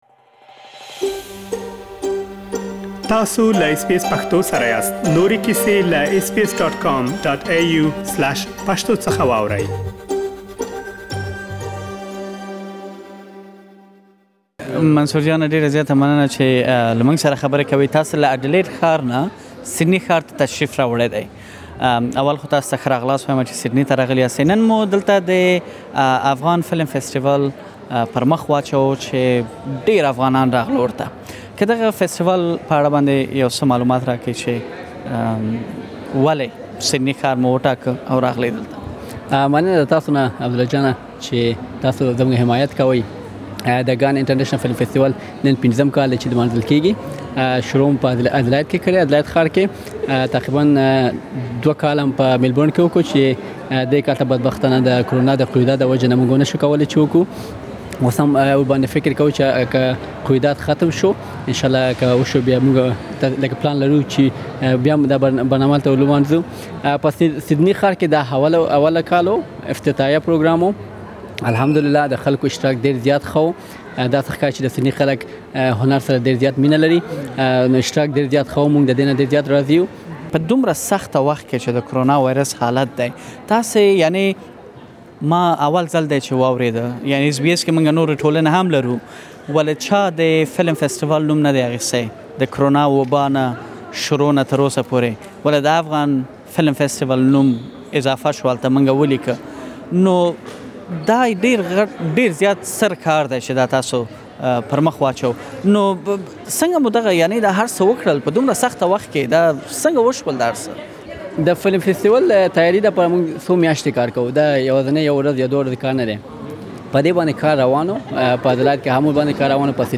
په مرکه کې اوريدلی شئ